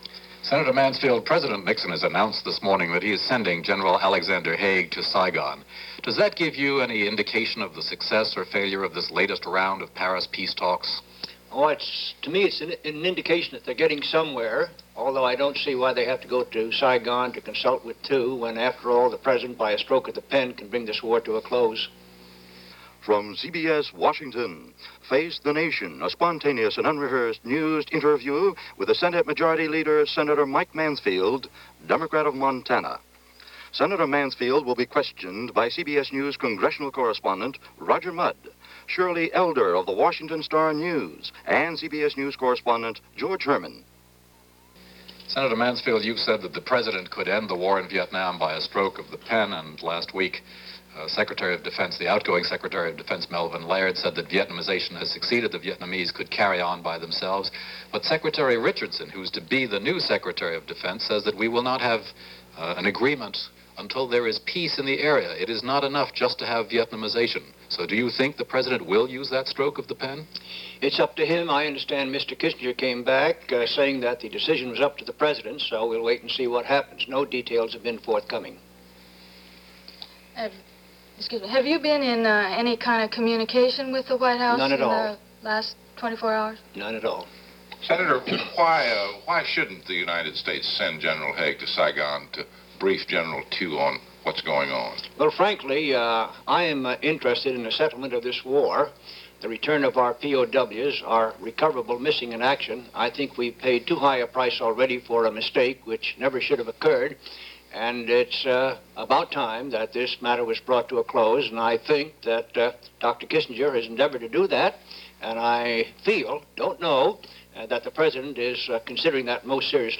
Fifty years ago this week, Senate Majority Leader Mike Mansfield was the subject of a half-hour grilling by members of the press for the weekly interview program Face The Nation. The subject, as it was most weeks, was Vietnam.
Here is that Face The Nation episode as it was broadcast on January 13, 1973.